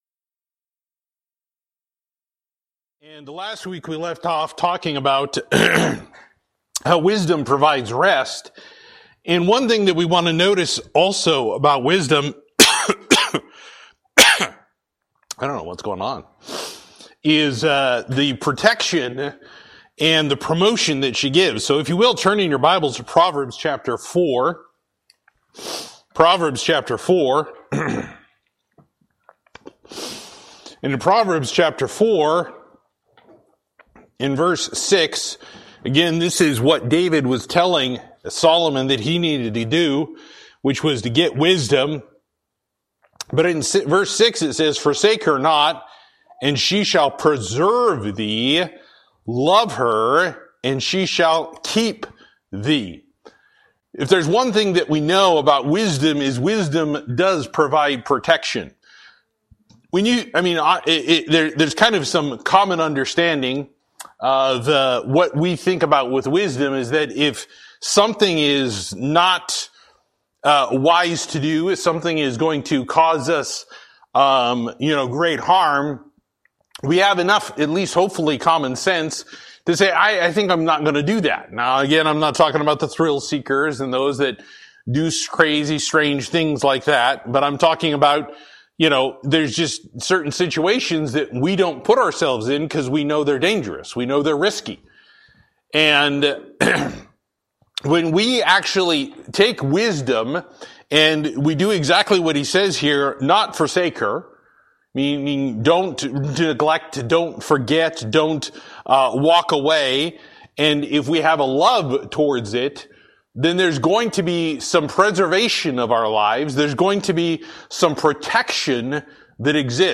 Service: Wednesday Night